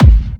Kick a.wav